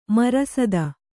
♪ marasada